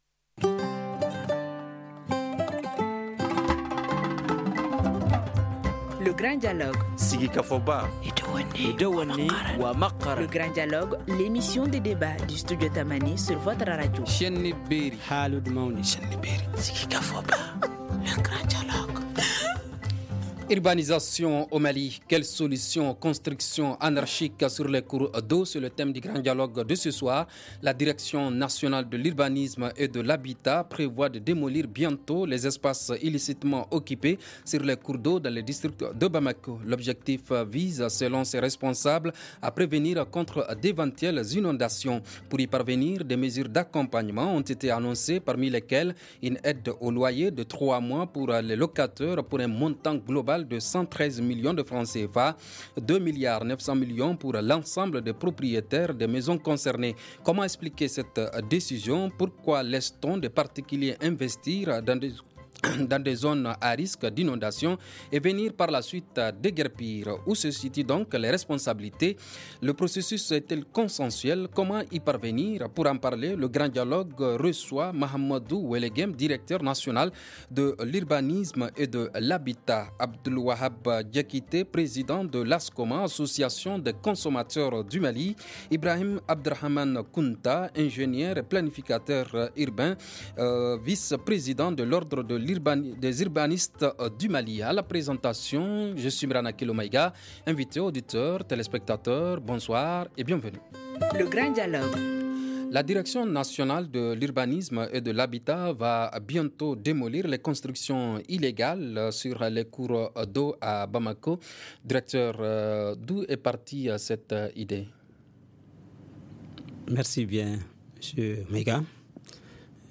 Pour en parler, le Grand Dialogue reçoit :
🎤 Mahamadou Ouologuem directeur national de l’Urbanisme et de l’Habitat;